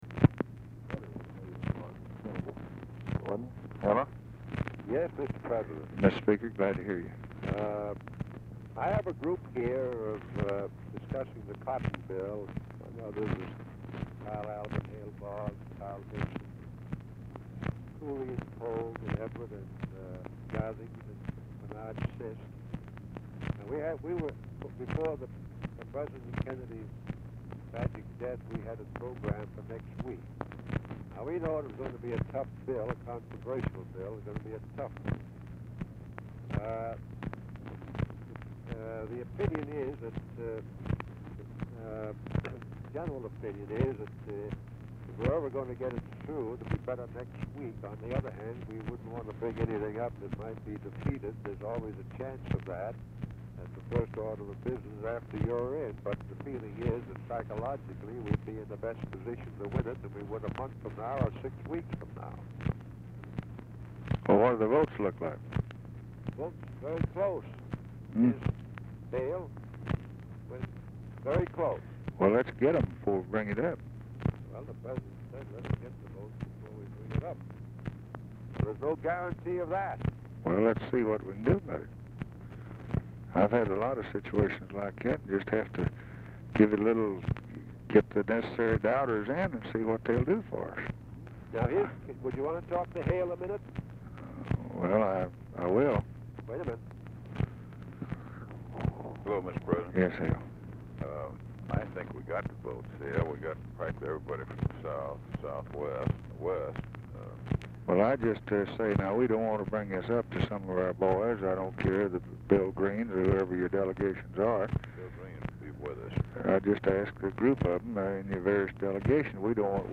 Telephone conversation # 64, sound recording, LBJ and JOHN MCCORMACK, 11/26/1963, 3:30PM | Discover LBJ
Format Dictation belt
Location Of Speaker 1 Oval Office or unknown location
Specific Item Type Telephone conversation Subject Agriculture Congressional Relations Legislation